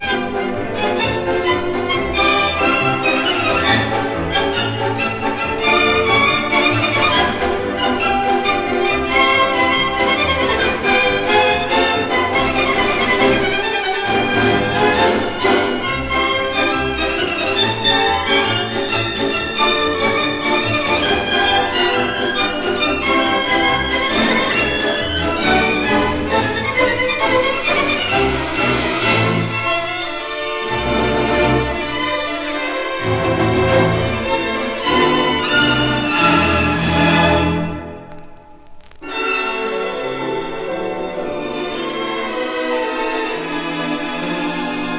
地域色豊かな軽やかさと哀愁、ダンス音楽としても楽しめて興味も湧く軽音楽集です。
Classical, Popular, World　USA　12inchレコード　33rpm　Mono